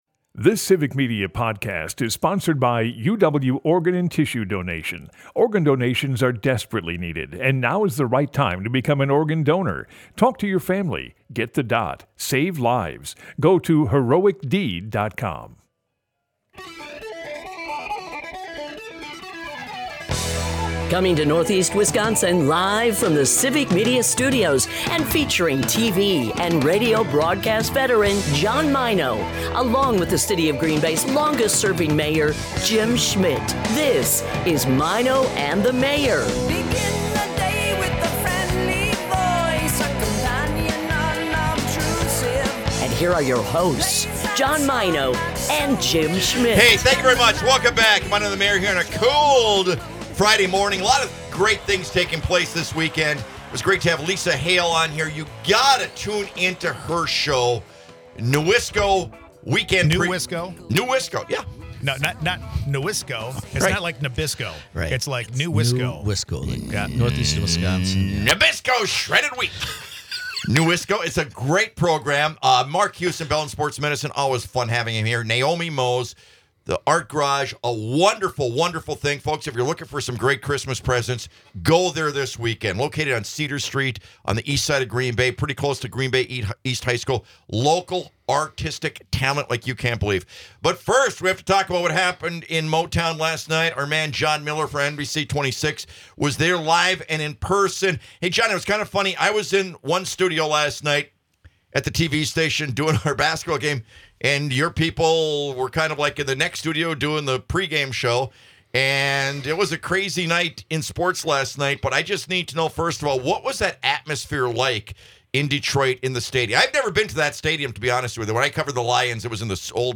It's always our favorite hour out of the entire week is when we get to talk football and enjoy some LIVE music! We get to talk about last night's Packer game.